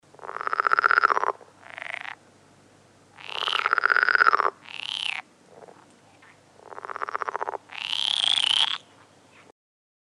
Звуки лягушек, жаб
Звук лягушачьего кваканья